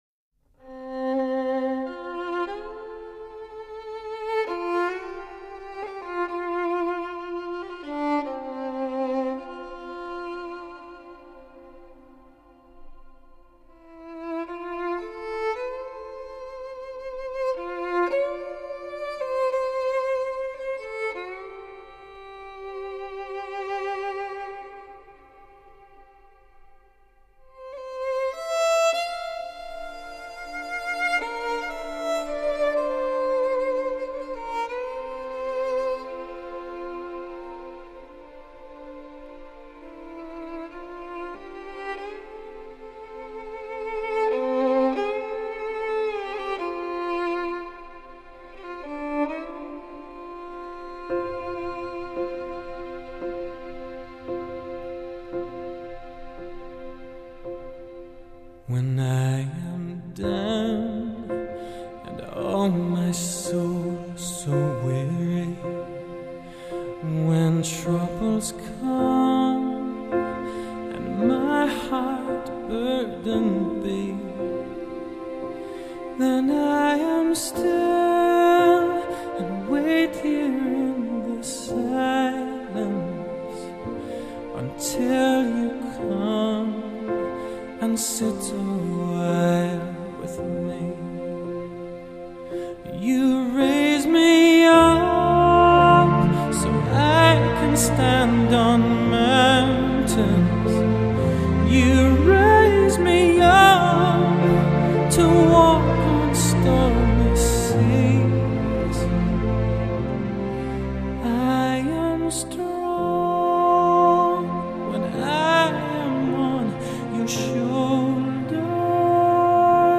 低沉耐人寻味专辑延续了小提琴和钢琴在乐曲中的主导地位和对古朴叙事歌谣的表现力
融合了爱尔兰空灵飘渺的乐风，挪威民族音乐及古典音乐